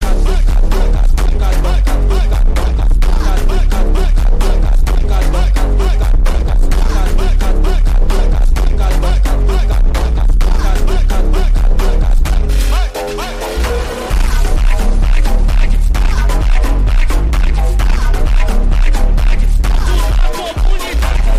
Phonk Bass Boosted